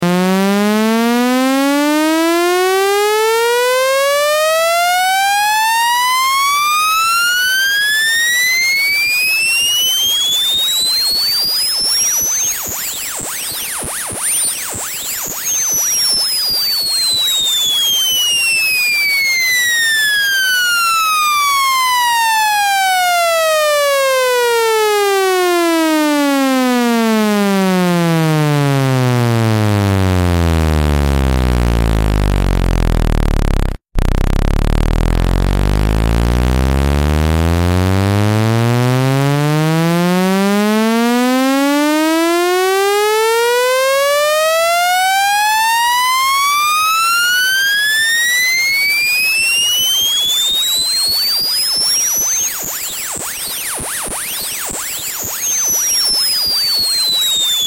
Sound Cleans Your Phone Speakers